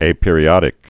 (āpîr-ē-ŏdĭk)